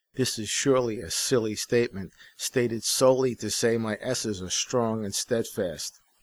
The “S’s” are very pronounced, as you know, but in terms of the recording I don’t think there is anything wrong. There are no sharp spikes in the frequency response.
I hear compression damage.
I get a hiss from the Mic. even though I put a Pop filter in front of it. I have a Shure SM57…
We all heard it slightly crisp, so you should probably deal with that.